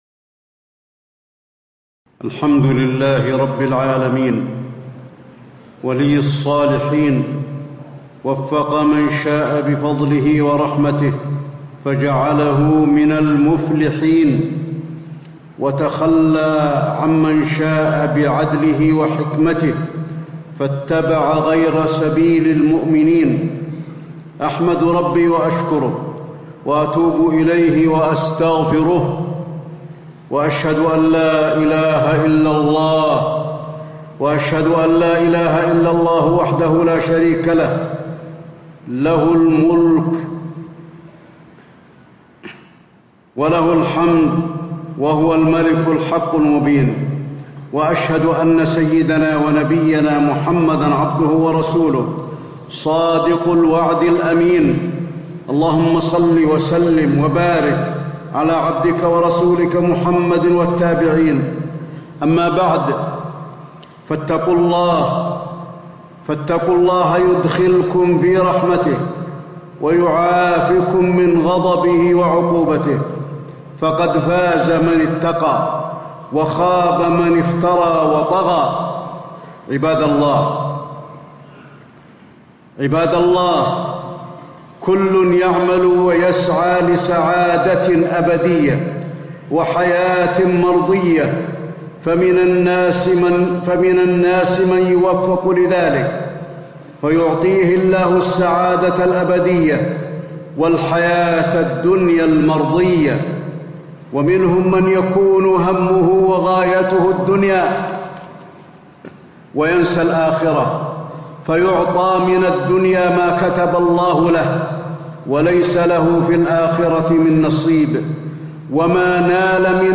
تاريخ النشر ١٥ صفر ١٤٣٧ هـ المكان: المسجد النبوي الشيخ: فضيلة الشيخ د. علي بن عبدالرحمن الحذيفي فضيلة الشيخ د. علي بن عبدالرحمن الحذيفي كيف يكون قلبك سليما